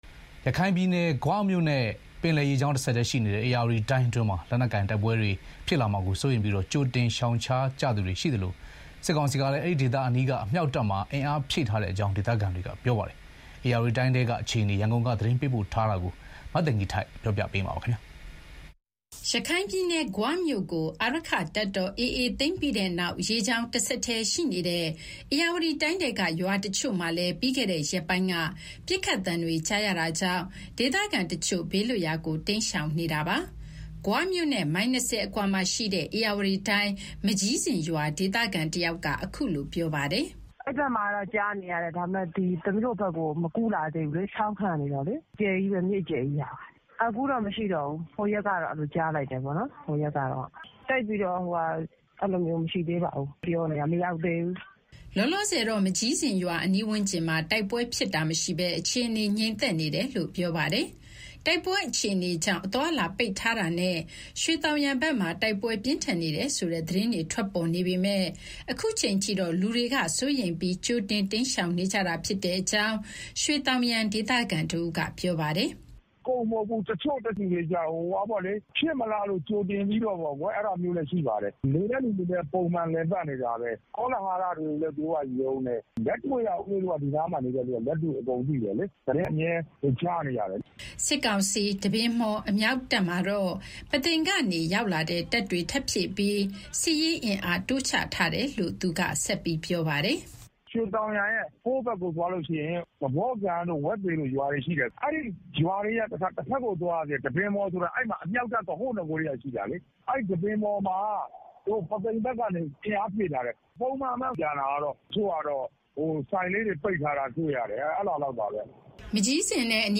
ရခိုင်ပြည်နယ်၊ ဂွမြို့နဲ့ ပင်လယ်ရေကြောင်း တဆက်တည်းရှိနေတဲ့ ဧရာဝတီတိုင်းအတွင်းမှာ လက်နက်ကိုင် တိုက်ပွဲတွေ ဖြစ်လာမှာကို စိုးရိမ်ပြီး ကြိုတင်ရှောင်ရှားကြသူတွေရှိသလို၊ စစ်ကောင်စီကလည်း အမြှောက်တပ်မှာ အင်အားဖြည့်ထားတဲ့အကြောင်း ဒေသခံတွေက ပြောပါတယ်။ ဧရာဝတီတိုင်းထဲက အခြေအနေ ရန်ကုန်ကနေ သတင်းပေးပို့ထားတာကိုပြောပြမှာပါ။